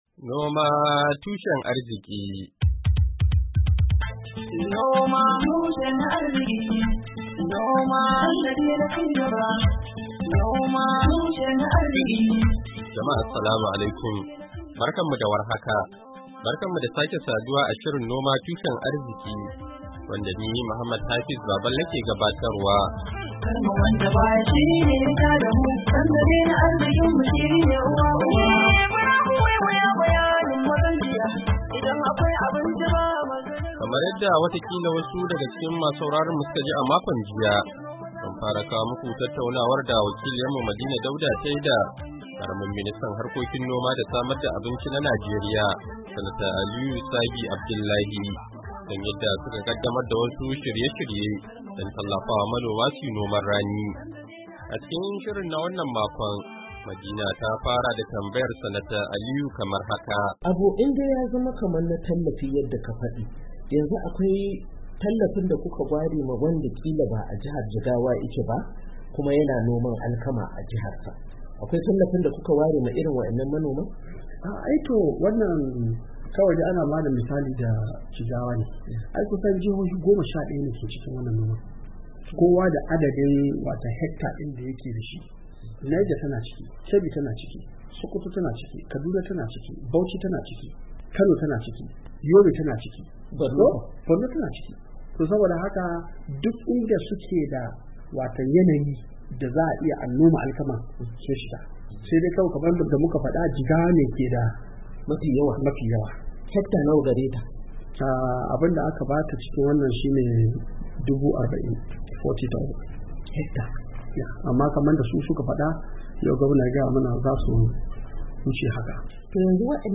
NOMA TUSHEN ARZIKI: Hira Da Ministan Harkokin Noma Da Samar Da Abinci Na Najeriya Kan Noman Rani Na Bana, Kashi Na Biyu - Janairu 30, 2024